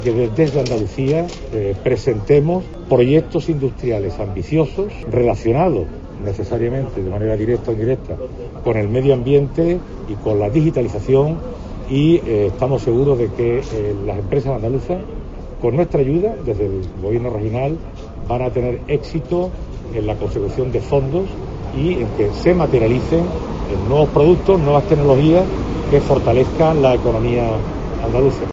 A este respecto y en declaraciones a los periodistas durante la inauguración del nuevo centro logístico de Silbon, empresa cordobesa del sector de la moda, Velasco ha señalado que, "de momento", lo único que se sabe "con certeza es el Perte (Proyecto Estratégico para la Recuperación y Transformación Económica) que se ha aprobado para el sector automovilístico", que "aquí en Andalucía es un sector que tiene un peso relativamente reducido".